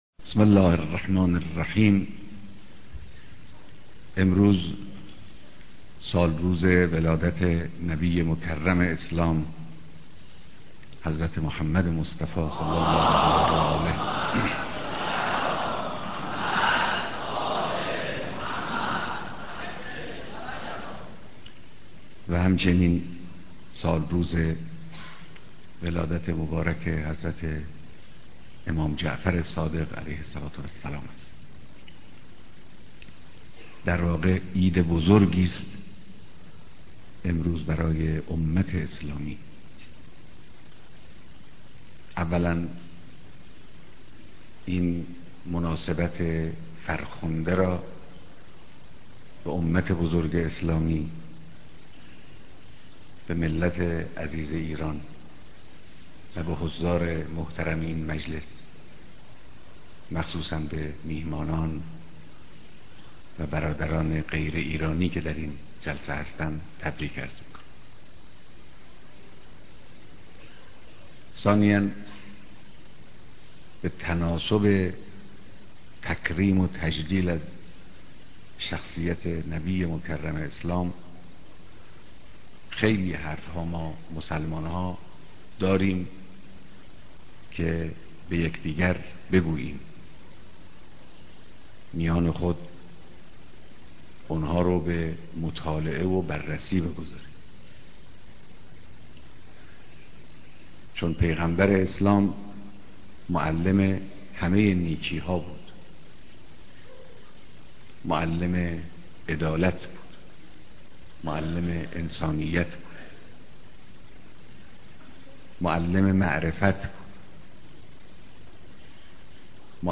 ديدار مسؤولان و كارگزاران نظام اسلامى و ميهمانان خارجى كنفرانس وحدت اسلامى